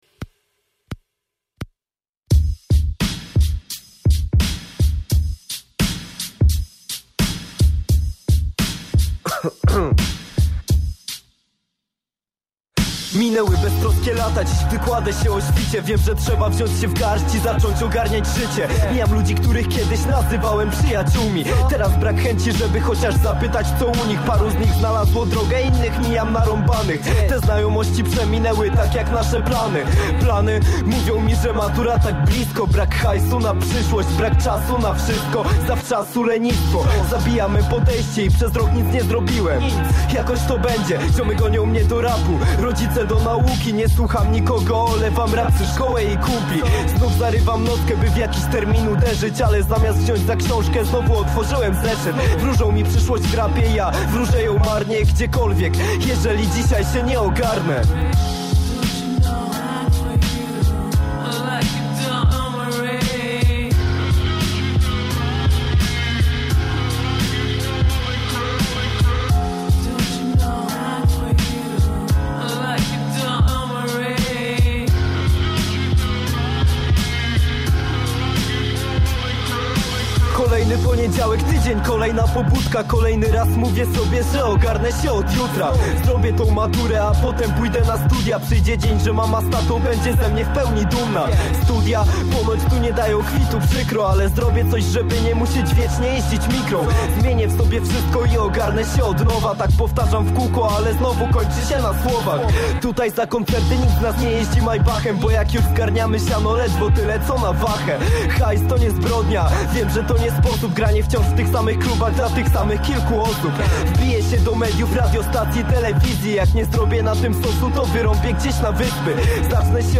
Raper, DJ i beatbox-er.
Artysta, za pomocą aparatu mowy wytwarzał dźwięki imitujące między innymi perkusję.